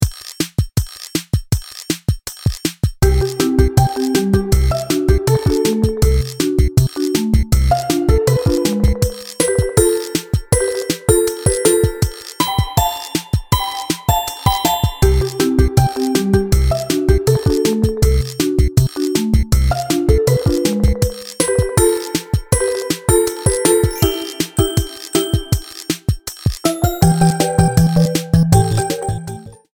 Trimmed, added fadeout